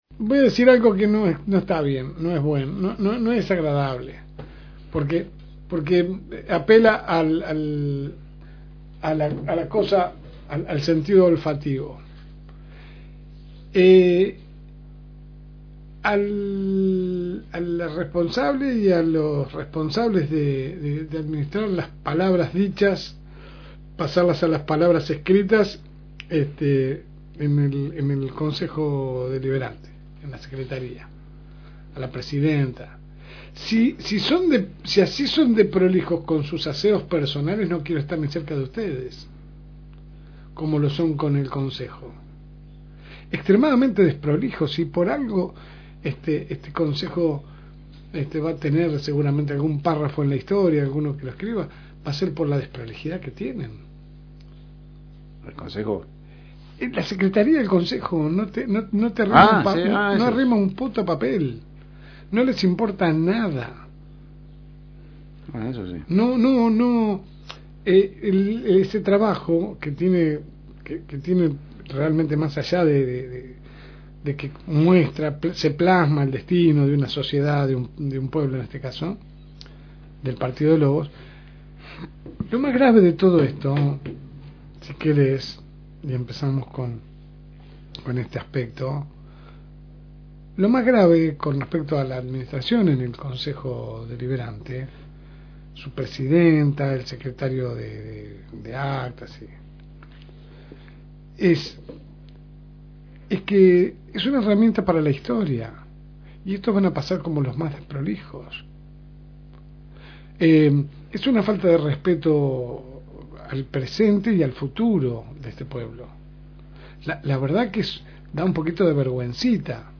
AUDIO – Editorial de LSM – FM Reencuentro